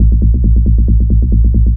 BL 136-BPM 1-A#.wav